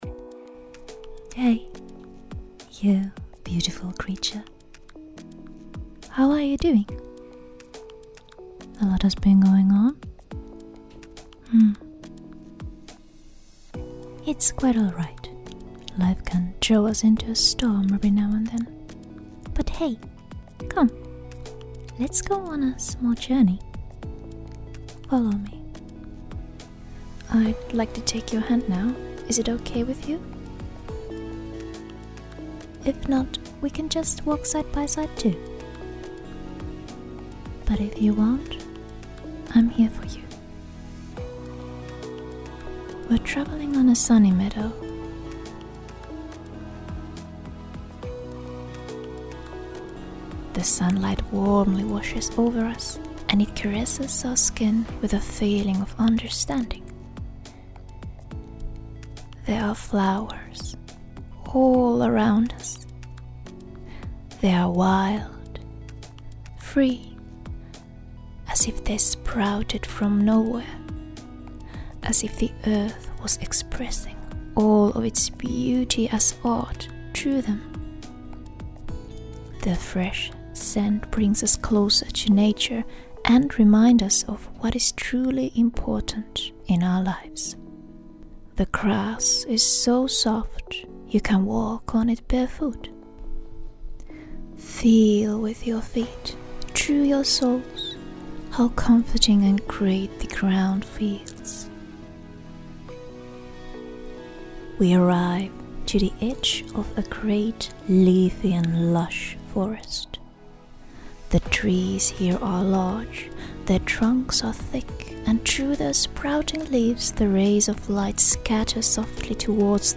lampropteradryocampa_meditation_ENGL.mp3